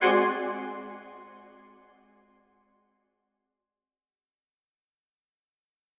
Brass Hits and Stabs
Brass , MIR , Reverb , Section , Single Chord , Spitfire , Staccato
Brass-High-Hit-2.wav